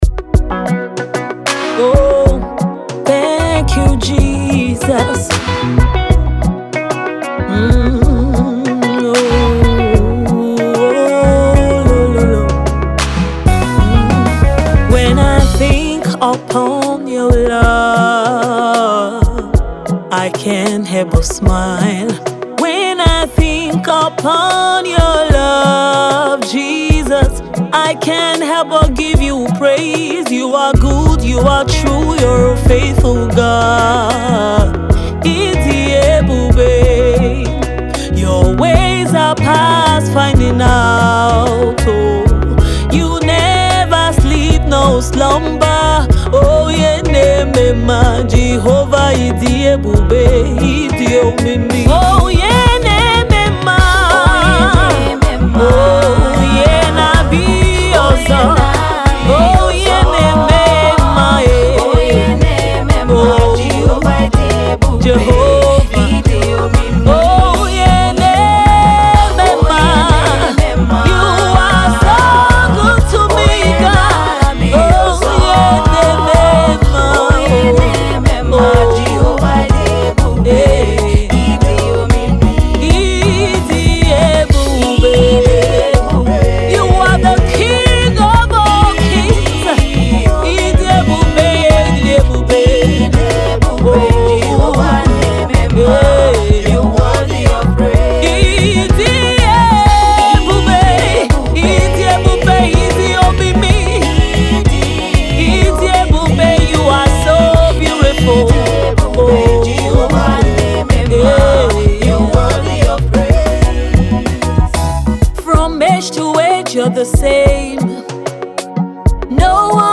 The Soul lifting praise tune
Quality Gospel music